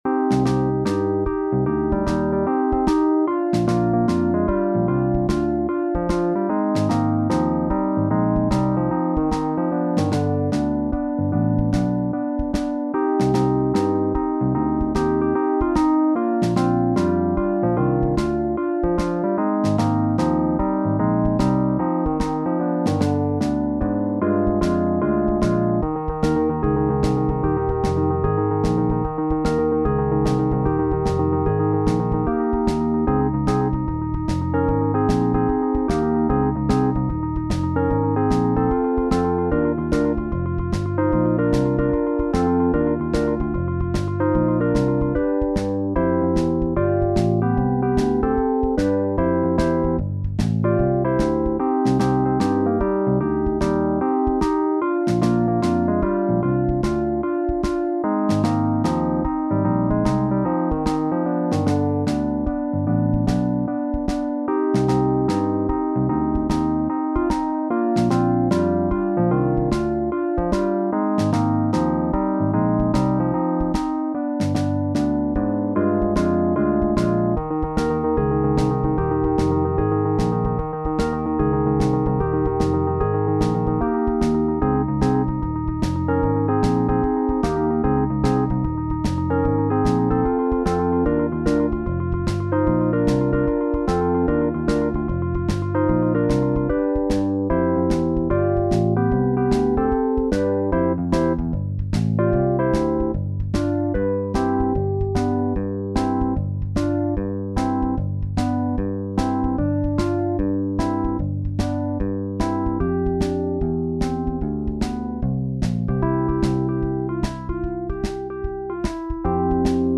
SATB | SSAA | SSAB